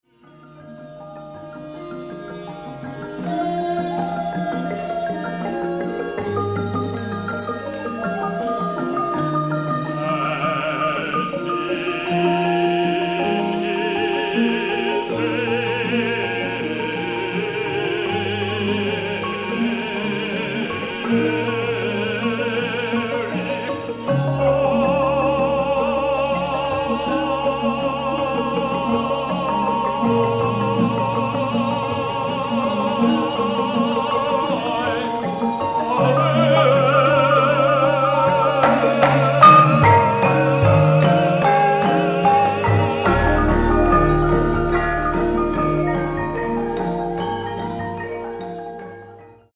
キーワード：空想民俗　創作楽器 　ガムラン　Harry Partch